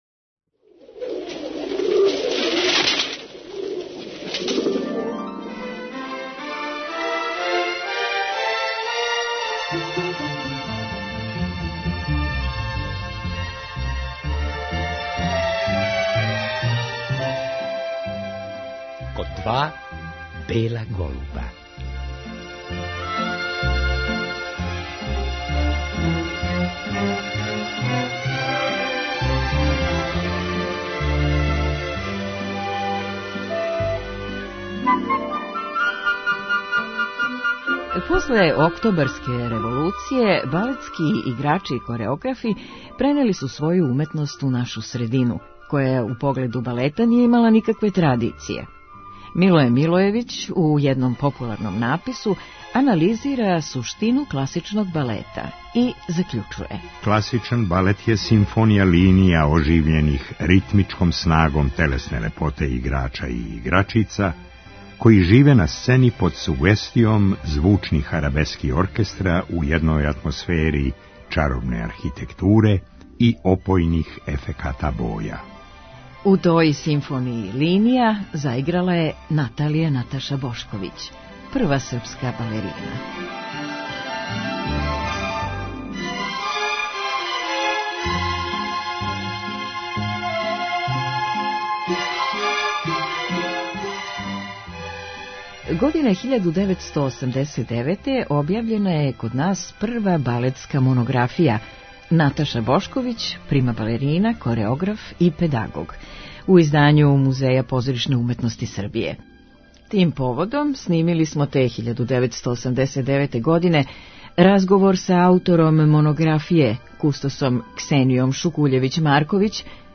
Тим поводом снимили смо, те 1989. године, разговар